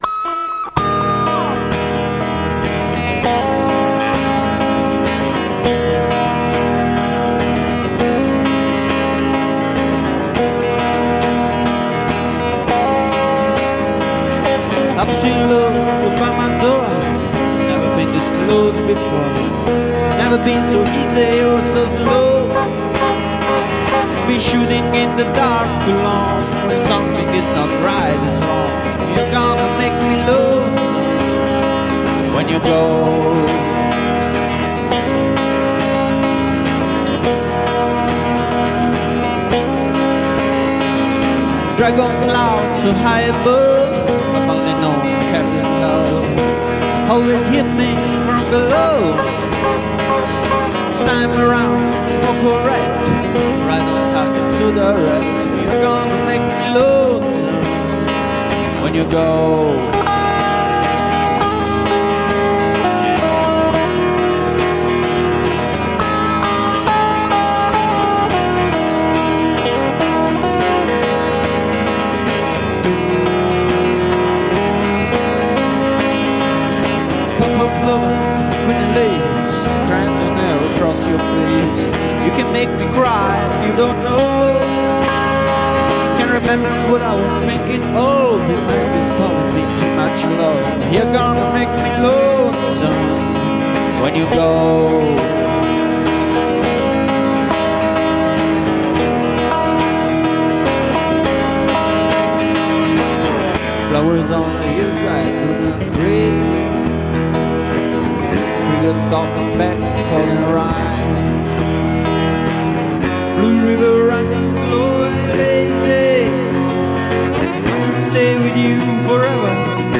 Many covers were home-recorded on audio CASSETTEs.